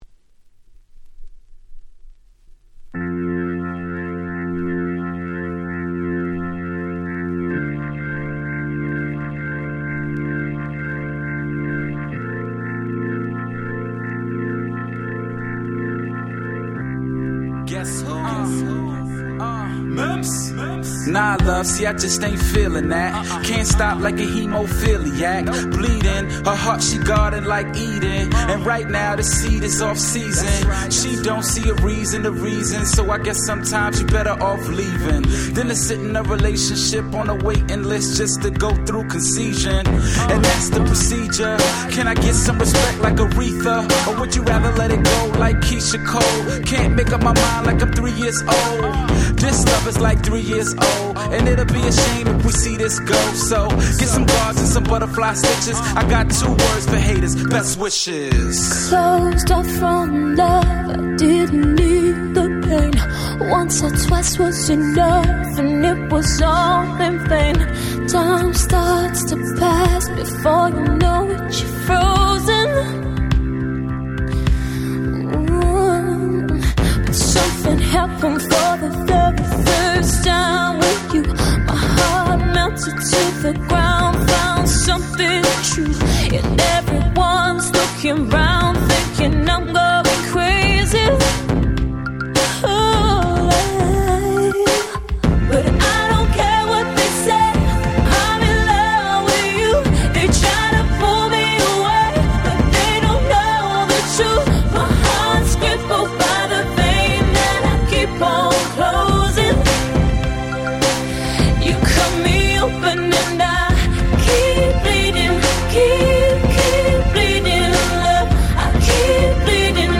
10' Very Nice R&B !!